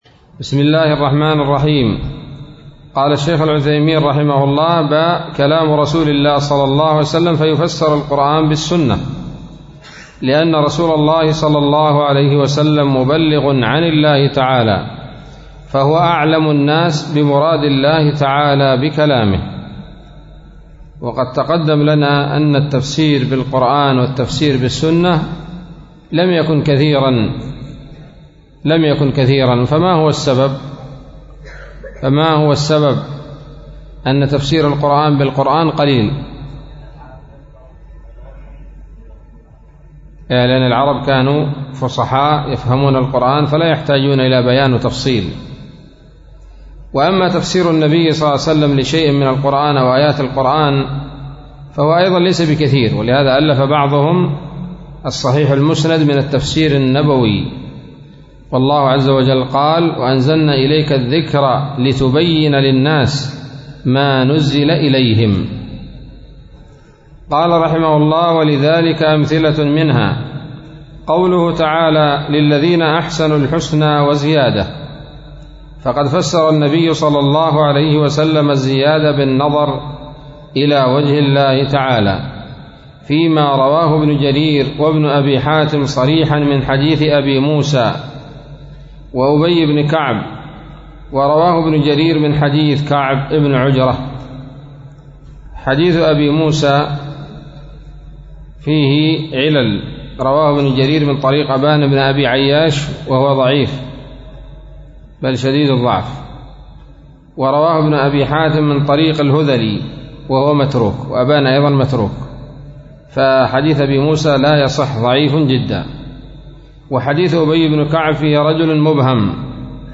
الدرس الحادي والعشرون من أصول في التفسير للعلامة العثيمين رحمه الله تعالى